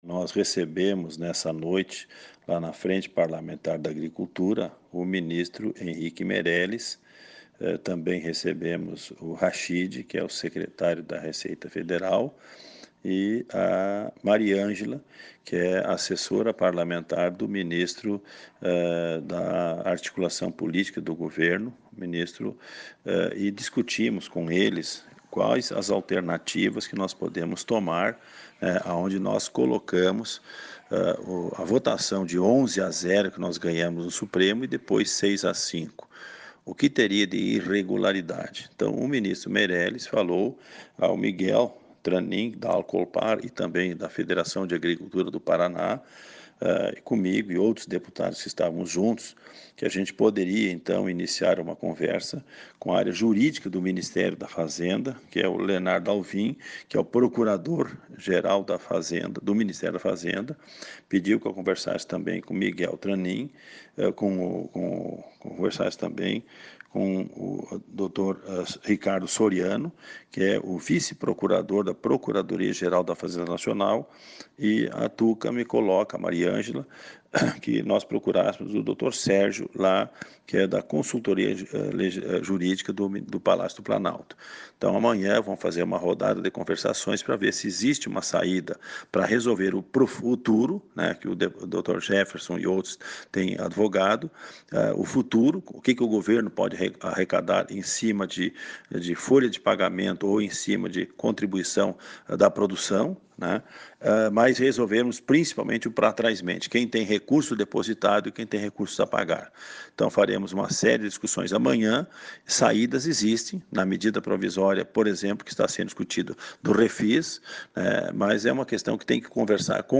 Confira o áudio completo do deputado Luis Carlos Heinze: